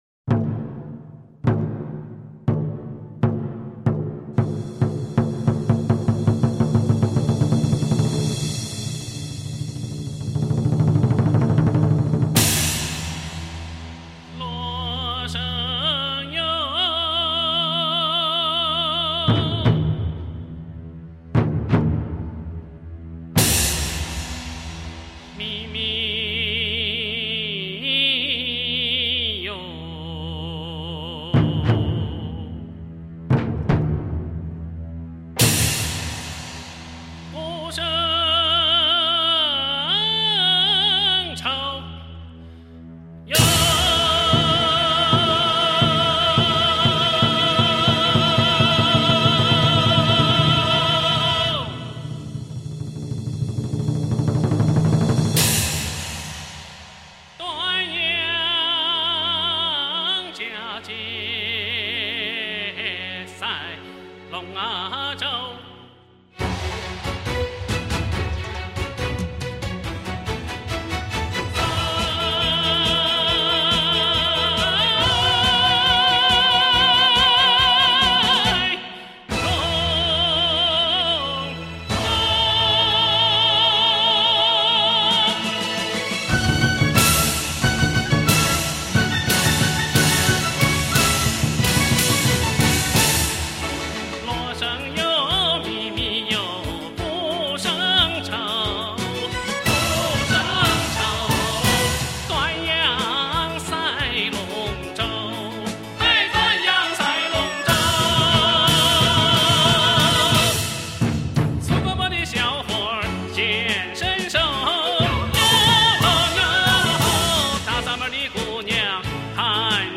不同风格的歌曲，他的演唱意深境阔，韵味浓厚，技巧精湛，咬字准确。
阳》已成为男高音广为传唱的优秀曲目